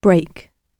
break-gb.mp3